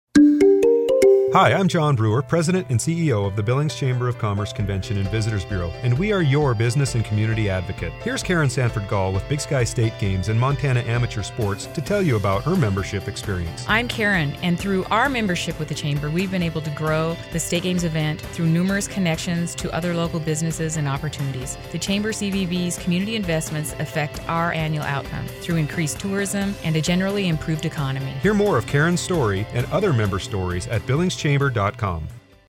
Billings Chamber :30 Spot
Chamber-30-Full-Mix.mp3